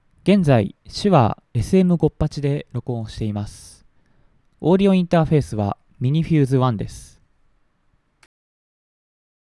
SHURE / SM58(ダイナミックマイク)
minifuse1-RECsm-58.wav